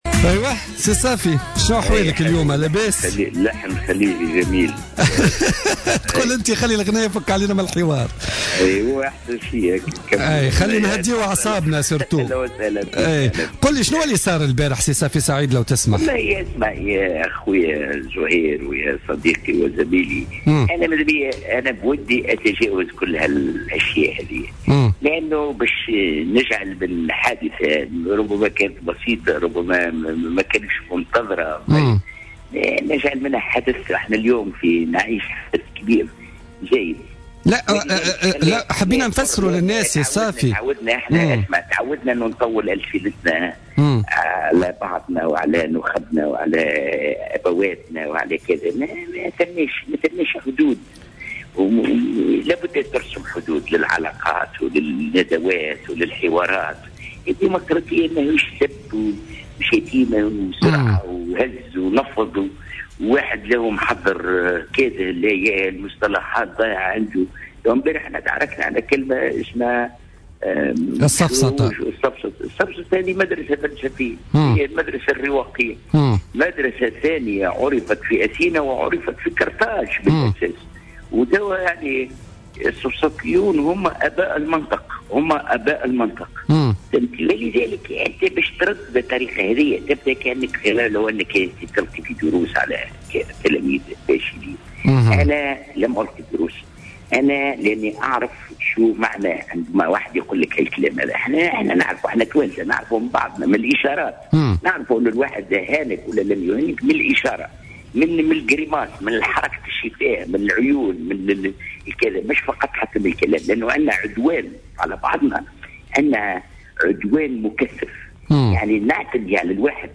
أعلن المرشح المستقل في الانتخابات الرئاسية الفارطة اليوم الخميس خلال حصة بوليتيكا على جوهرة أف أم عن تأسيس حزب سياسي بعد أن تبين من خلال الانتخابات أن لا مكان للمستقلين في المشهد السياسي ولا يمكن أن ينجح دون "ماكينة حزبية".